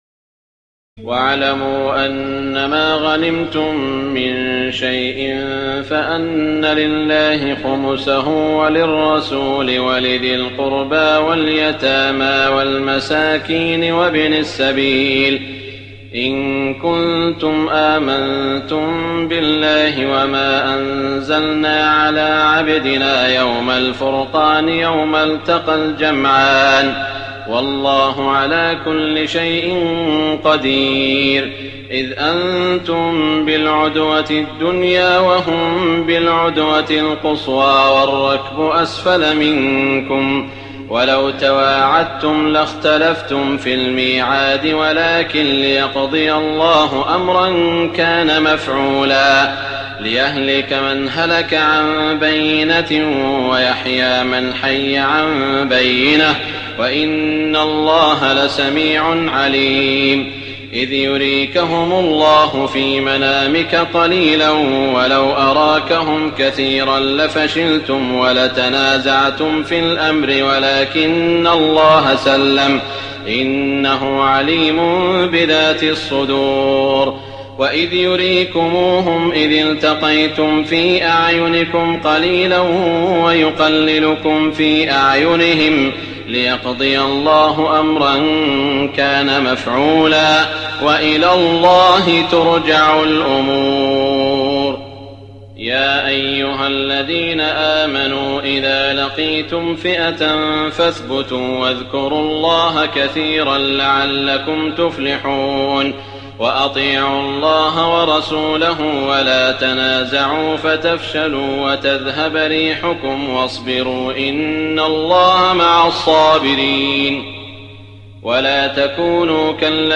تراويح الليلة التاسعة رمضان 1419هـ من سورتي الأنفال (41-75) و التوبة (1-33) Taraweeh 9 st night Ramadan 1419H from Surah Al-Anfal and At-Tawba > تراويح الحرم المكي عام 1419 🕋 > التراويح - تلاوات الحرمين